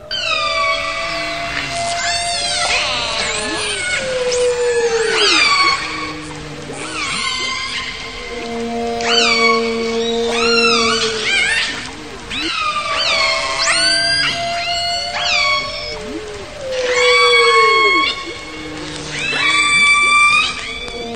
Kategorien: Tierstimmen